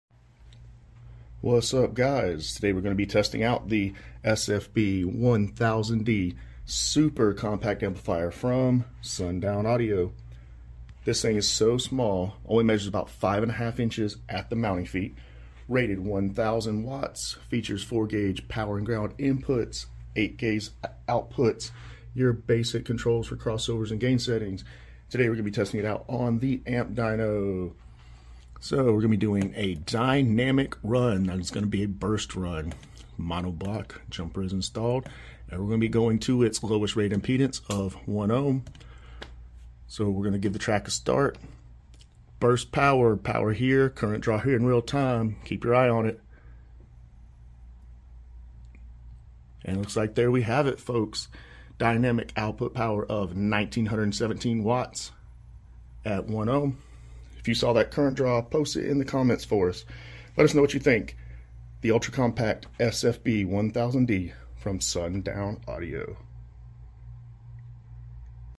Sundown Audio SFB-1000D dynamic 1ohm amp dyno test. car audio bass subwoofer amplifier sound videos